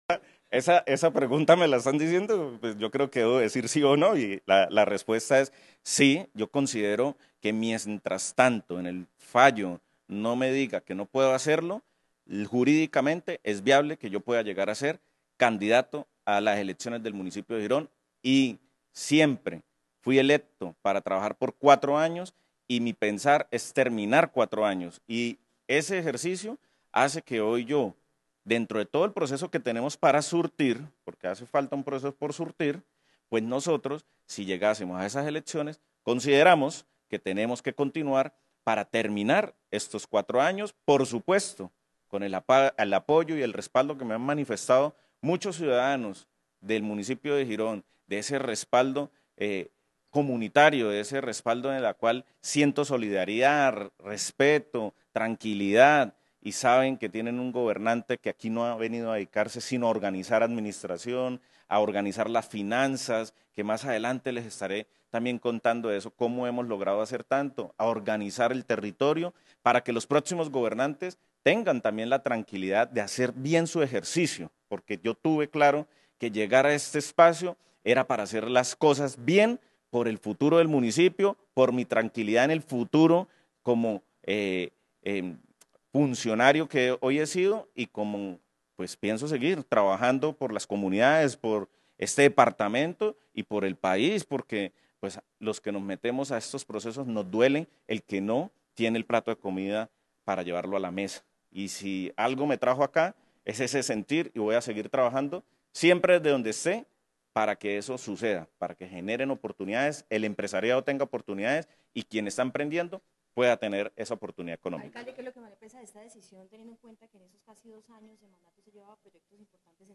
Campo Elias Ramírez, alcalde de Girón